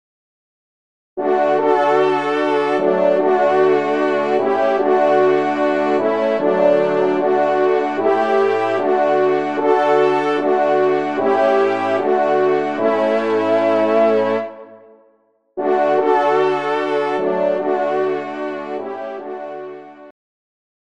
Genre : Fantaisie Liturgique pour quatre trompes
ENSEMBLE